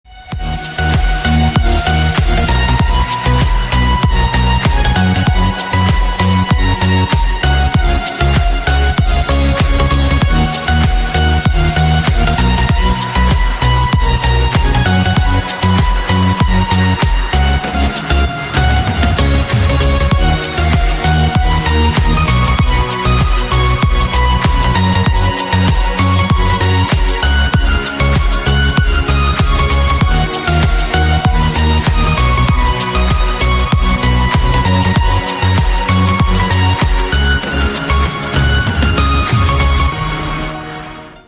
• Качество: 320, Stereo
громкие
Electronic
EDM
electro house